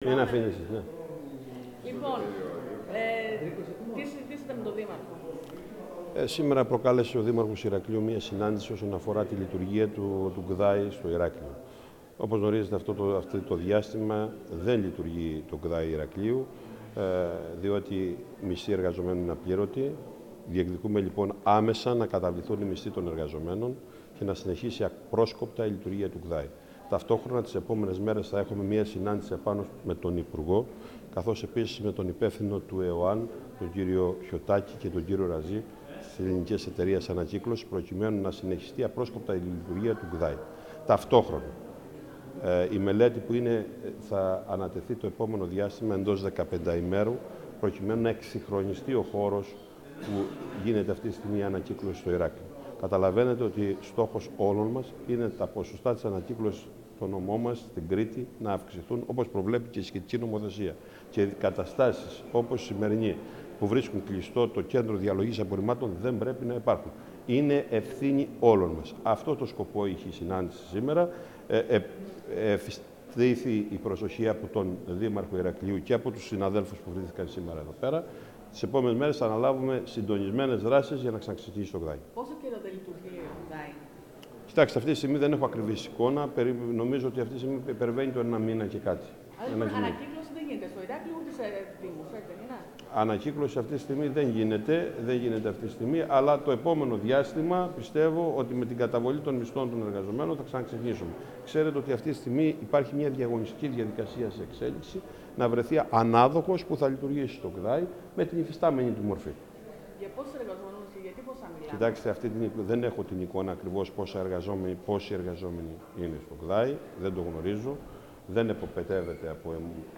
Οι δηλώσεις του Δημάρχου Βασίλη Λαμπρινού για την σημερινή συνάντηση: